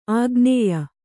♪ āgnēya